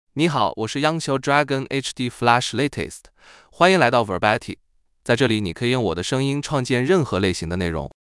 Yunxiao Dragon HDFlash Latest — Male Chinese (Mandarin, Simplified) AI Voice | TTS, Voice Cloning & Video | Verbatik AI
Yunxiao Dragon HDFlash Latest is a male AI voice for Chinese (Mandarin, Simplified).
Voice sample
Listen to Yunxiao Dragon HDFlash Latest's male Chinese voice.
Male